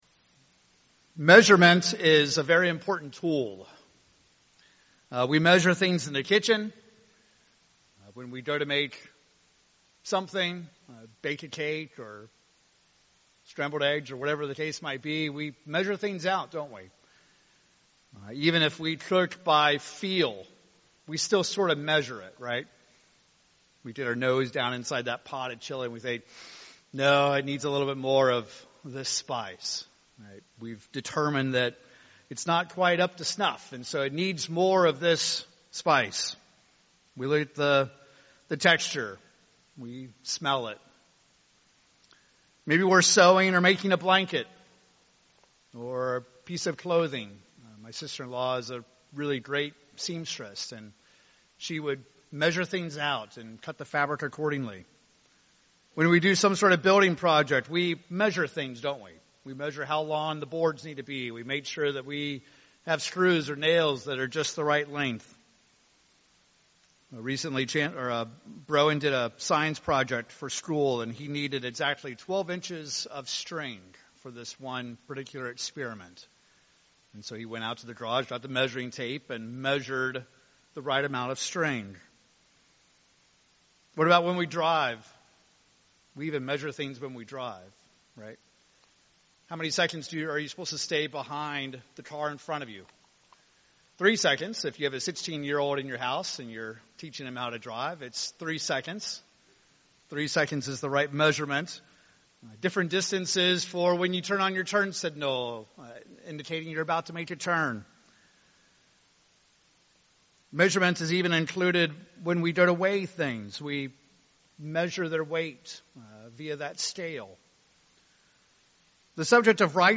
As this sermon series concludes, we will examine three critical questions of righteousness, culminating by considering who measures your righteousness.